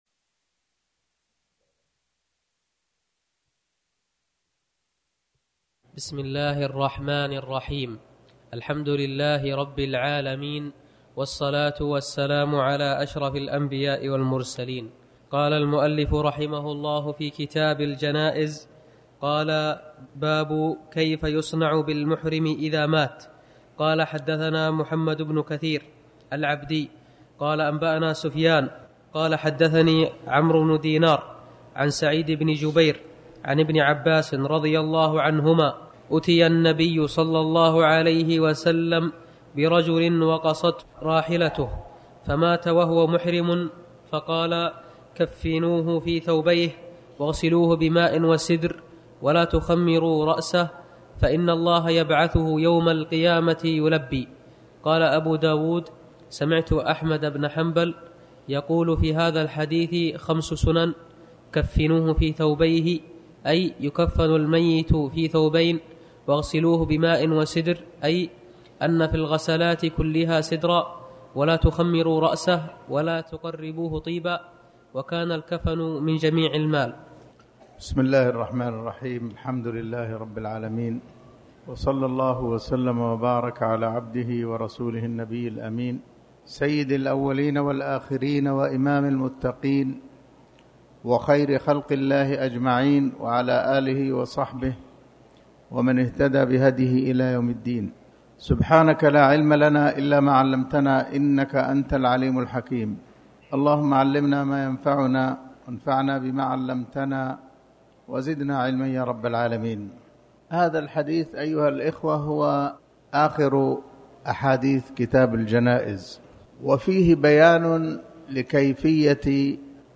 تاريخ النشر ١ ربيع الثاني ١٤٤٠ هـ المكان: المسجد الحرام الشيخ